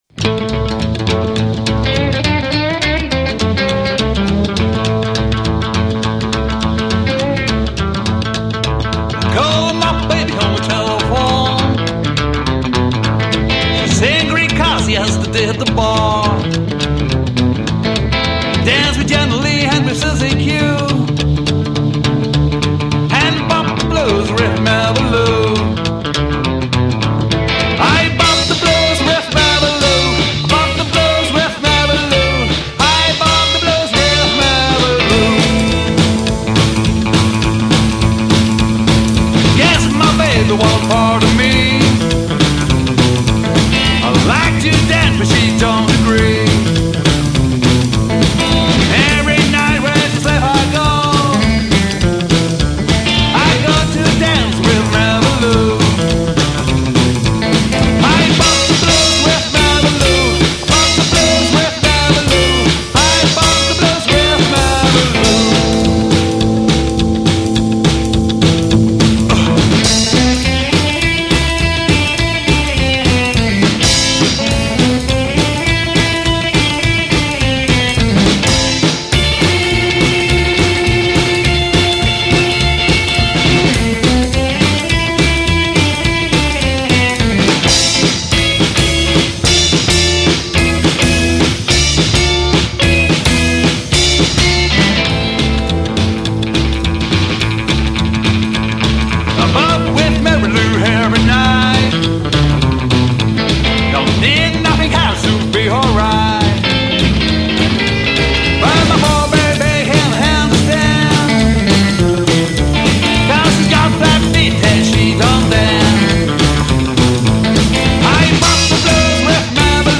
à la contrebasse
à la batterie !